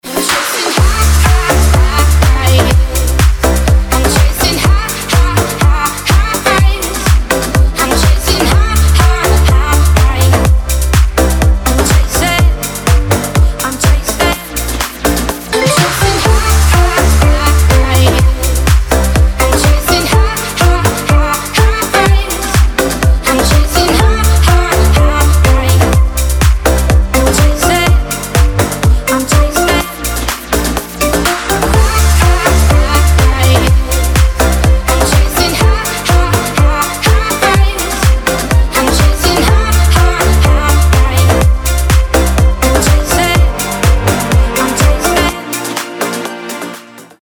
• Качество: 320, Stereo
женский вокал
EDM
скрипка
club
Indie Dance
ксилофон